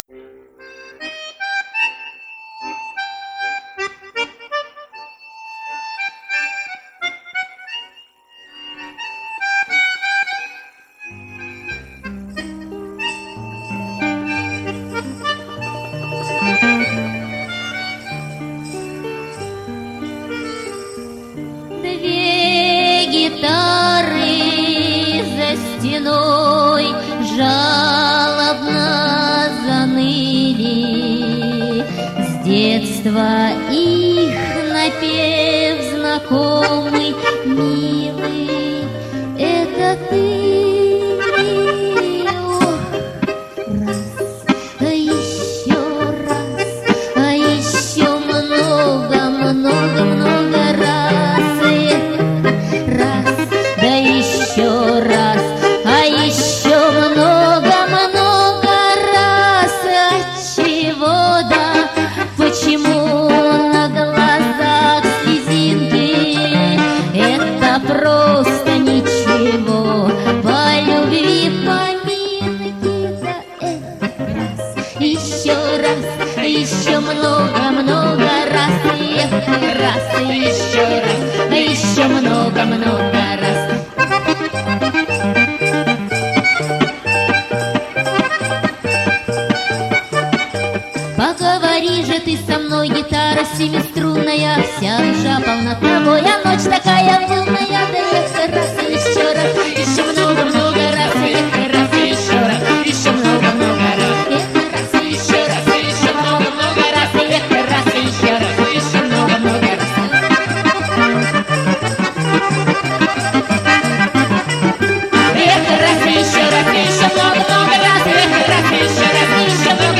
Жанр: Поп-фолк